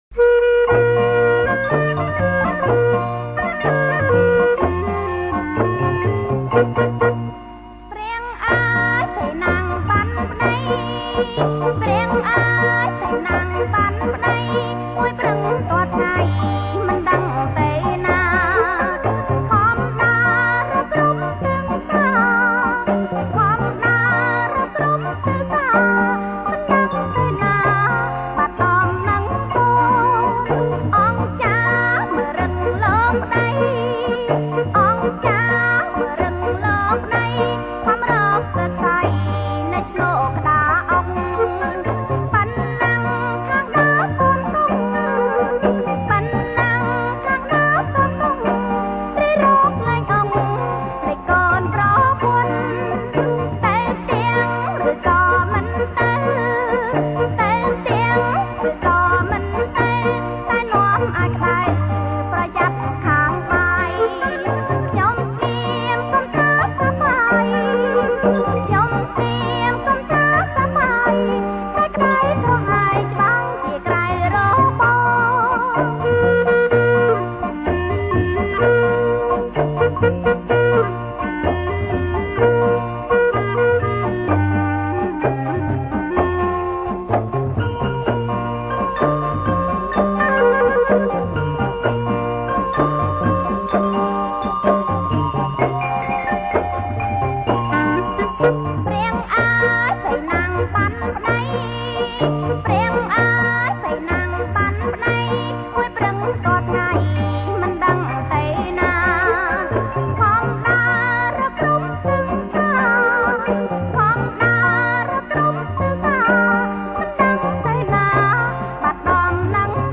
• ប្រគំជាចង្វាក់ រាំវង់